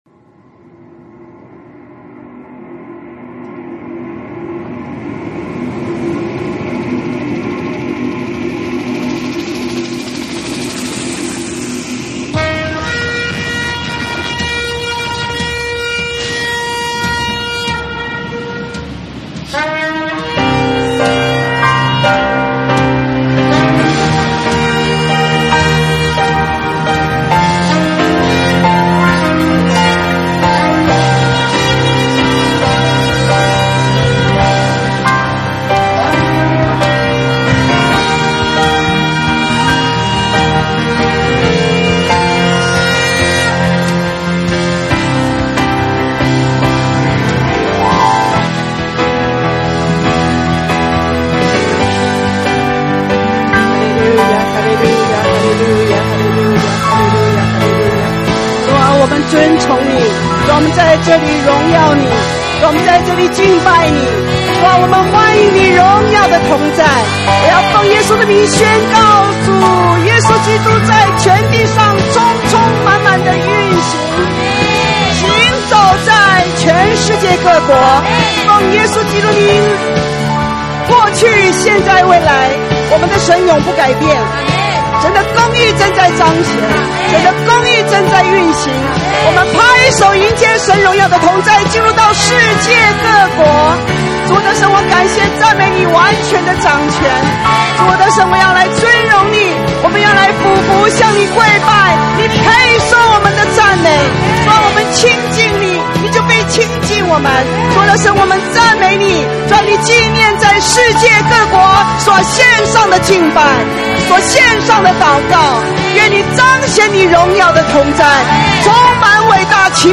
先知性敬拜禱告
【主日信息】： 【找回起初的愛】